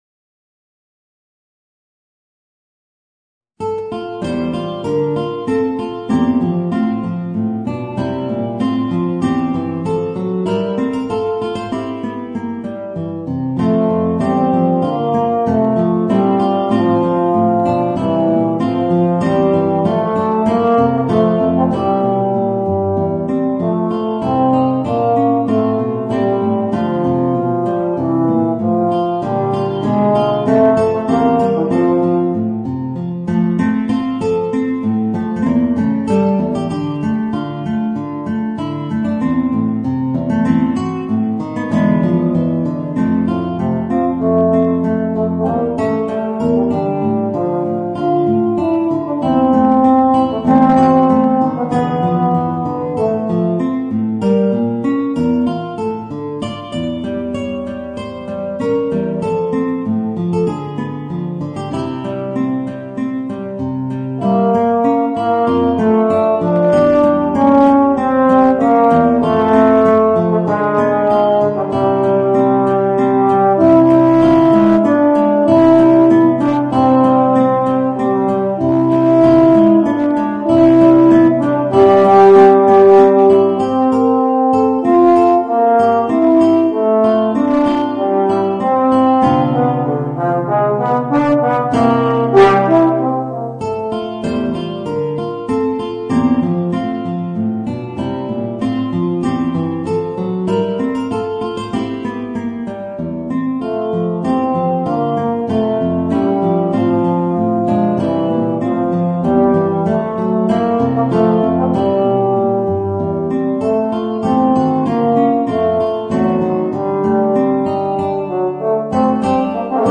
Voicing: Guitar and Euphonium